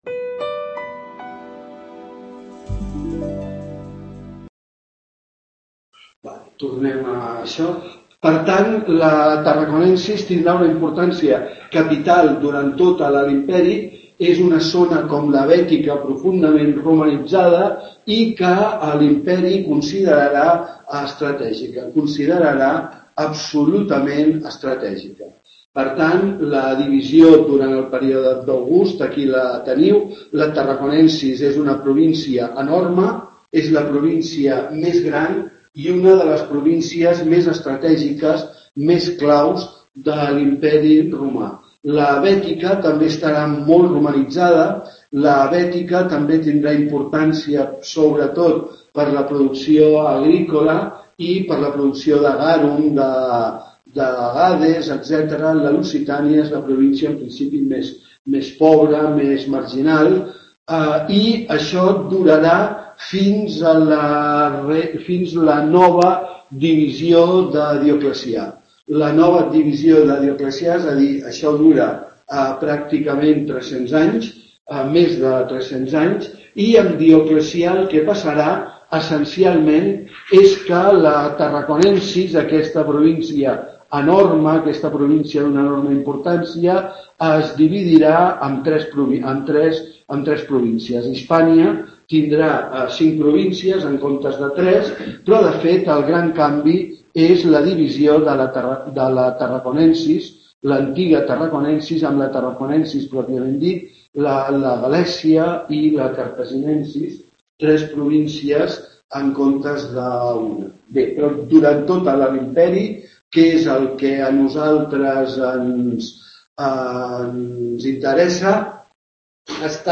tutoría acerca del alto imperio en Hispania